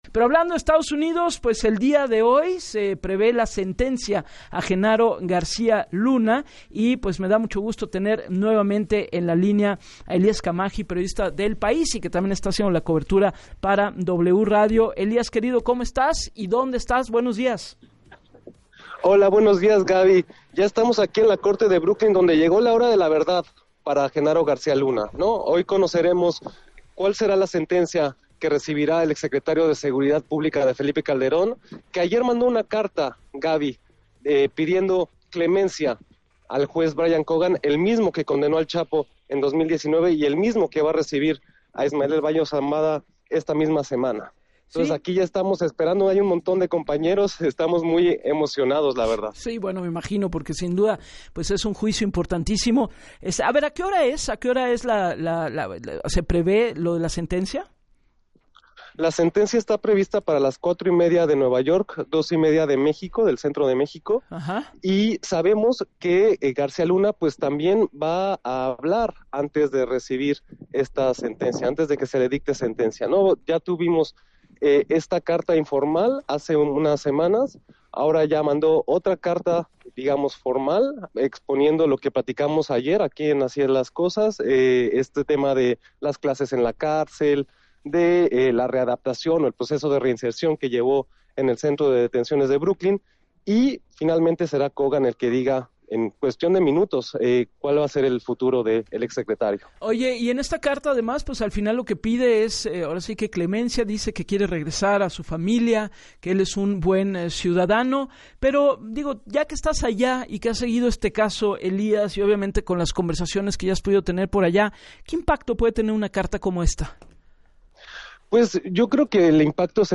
En entrevista para “Así las Cosas” con Javier Risco, detalló que al puerto de Acapulco lo abastecen de agua potable 4 fuentes y 3 de estas se encuentran a lo largo del río Papagayo y una más en Lomas de Chapultepec, que suministran más del 97 del agua potable.